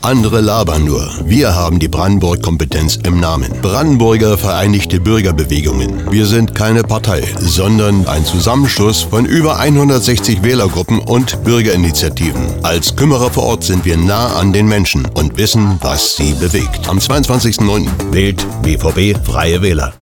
Unsere Radiospots:
03-bvb-fw-radiospot-brandenburg-kompetenz-20sek.mp3